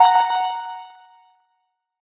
Index of /phonetones/unzipped/Google/Android-Open-Source-Project/notifications/ogg